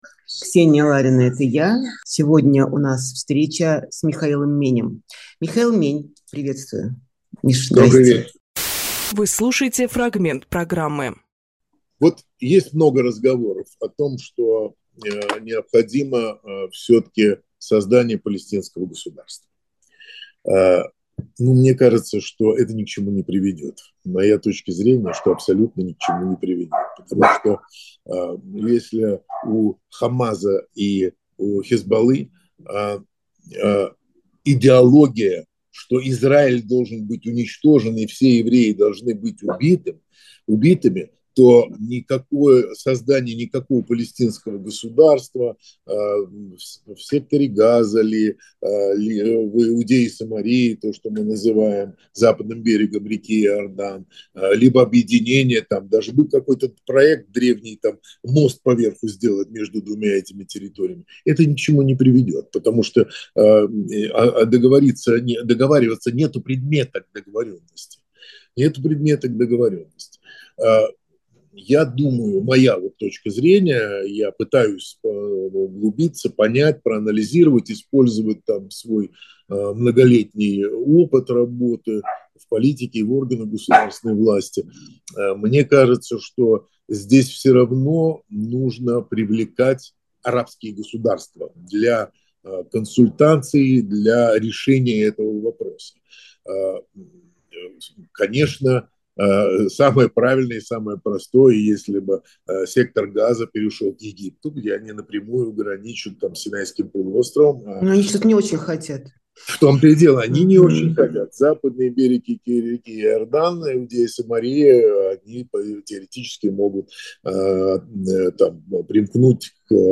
Фрагмент эфира от 14.10